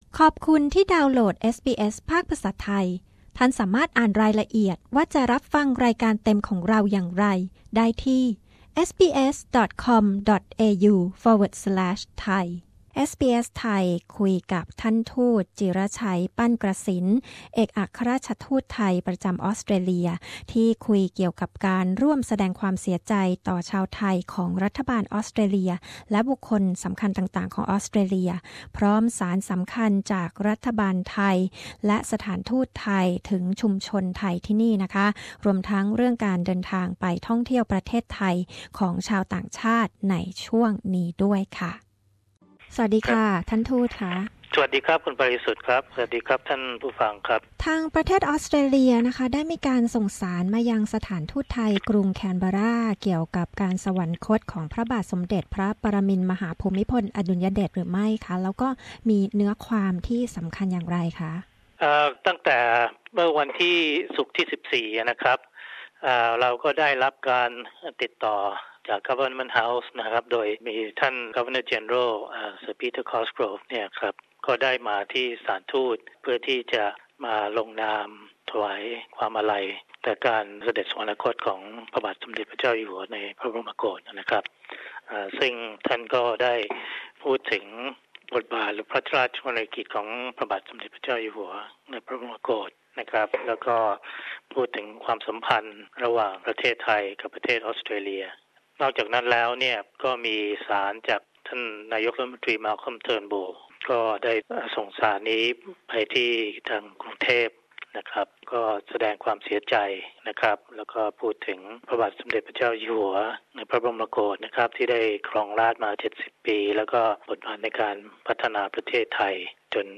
นายจิระชัย ปั้นกระษิณ เอกอัครราชทูตกล่าวถึงการร่วมแสดงความเสียใจของรัฐบาลออสเตรเลีย และบุคคลสำคัญต่างๆ หลังการสวรรคตของพระเจ้าอยู่หัวภูมิพลอดุลยเดช พร้อมสารจากรัฐบาลไทยและสถานทูต ถึงชุมชนไทยในออสเตรเลีย